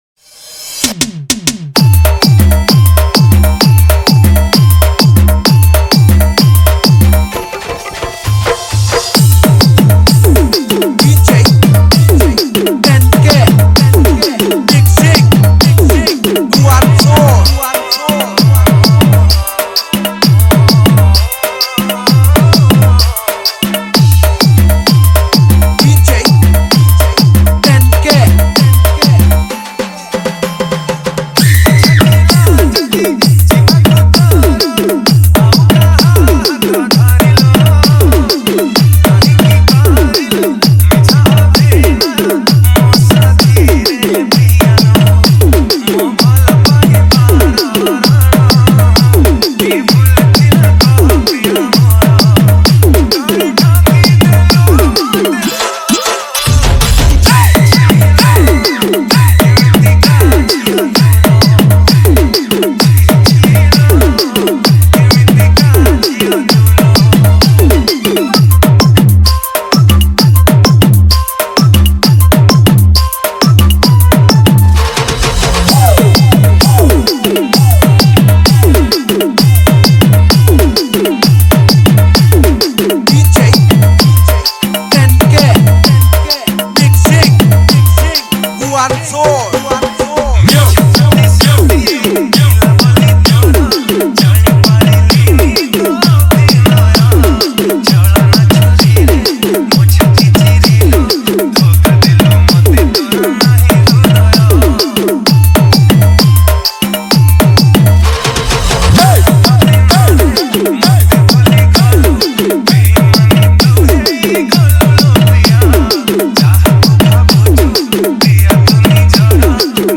Category:  New Odia Dj Song 2023